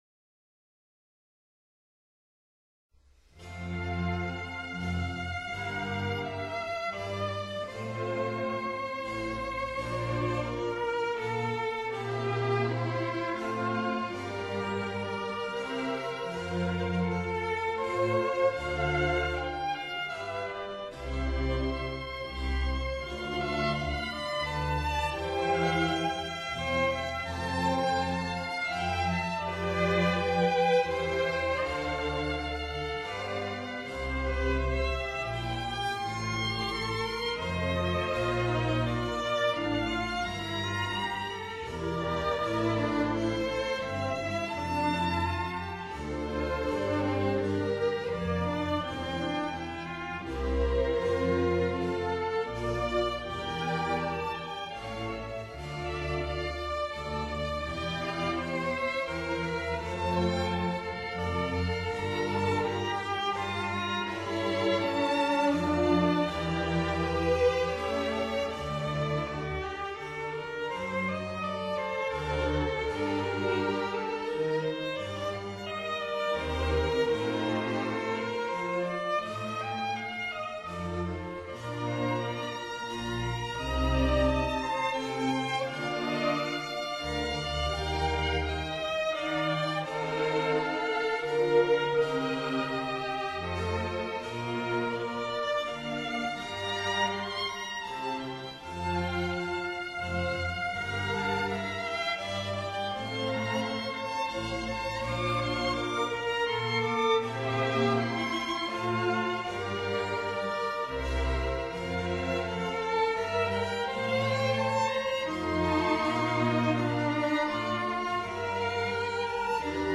Bach-Double-Violin-Concerto-in-D-minor-2nd-movement-Largo-1.mp3